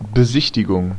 Ääntäminen
Ääntäminen US Tuntematon aksentti: IPA : /ˈsaɪt.ˌsiː.ɪŋ/ Haettu sana löytyi näillä lähdekielillä: englanti Käännös Ääninäyte Substantiivit 1.